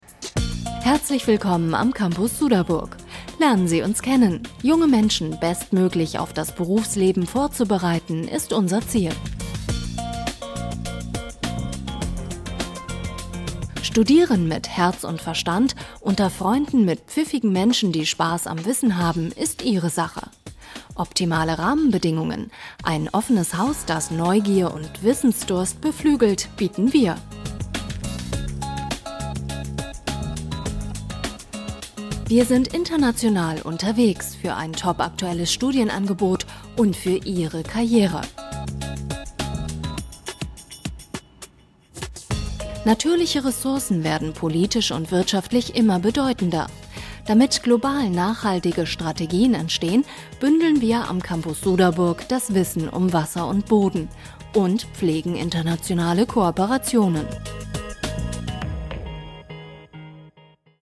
Studio-Sprecherin mit junger, frischer Stimme.
norddeutsch
Sprechprobe: Industrie (Muttersprache):